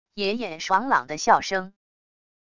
爷爷爽朗的笑声wav音频